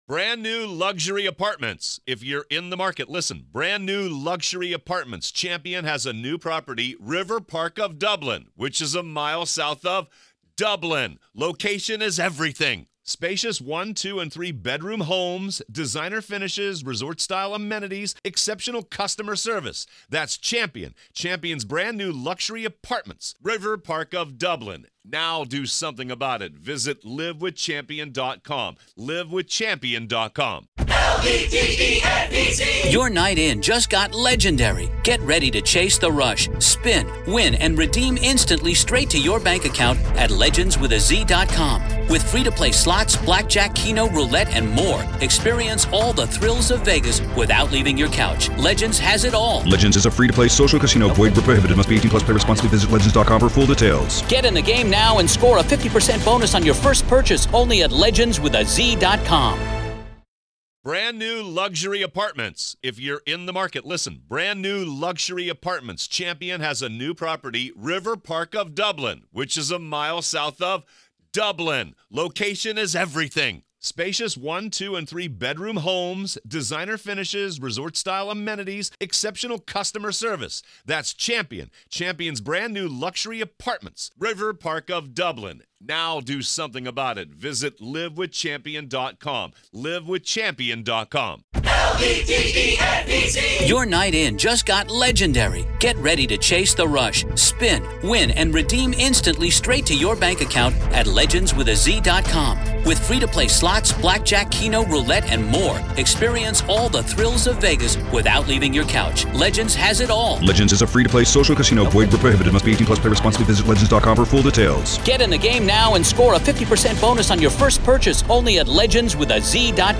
Former prosecutor